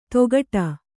♪ togaṭa